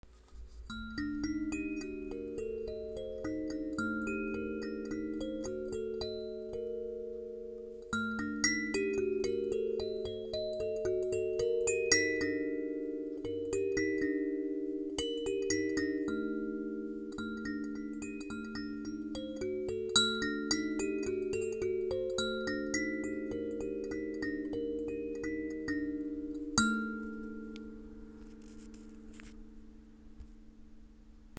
Sansula-Hokema Basic Hijaz 440 Hz